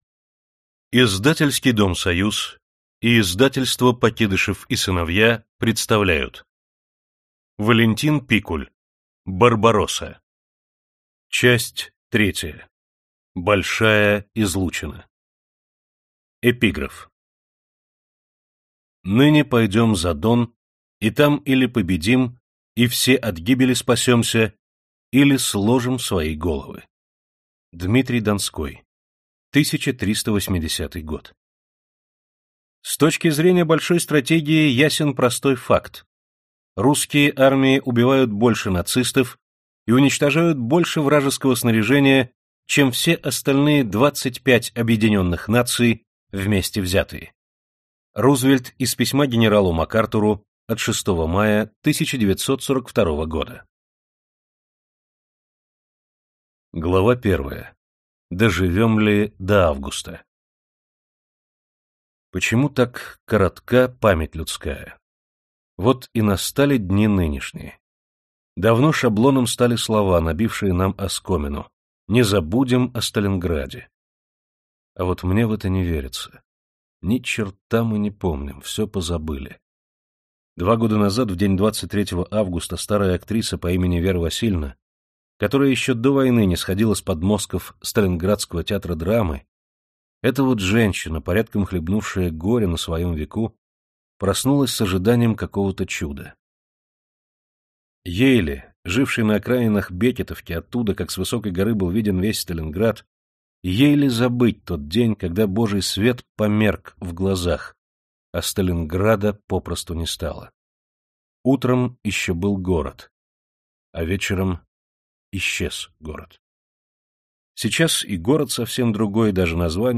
Аудиокнига Барбаросса. Часть 3. Большая излучина | Библиотека аудиокниг